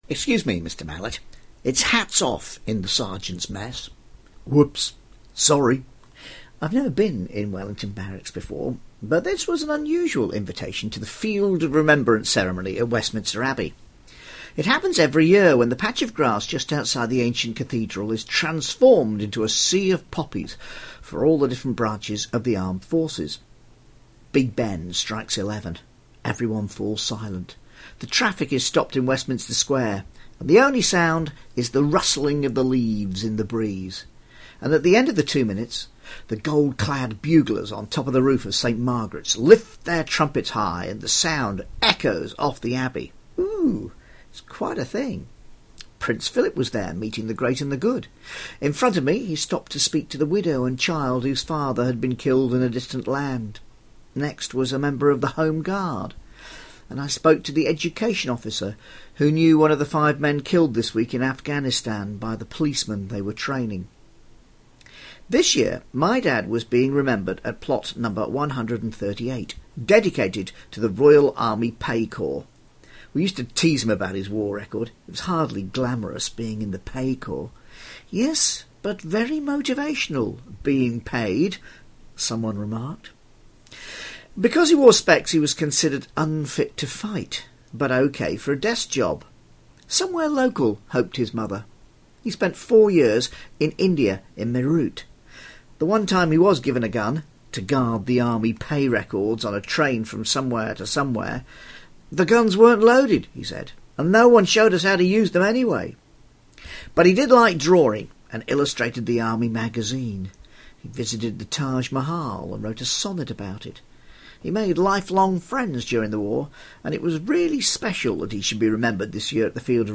It's moving to be invited to the field of remembrance at Westminster Abbey.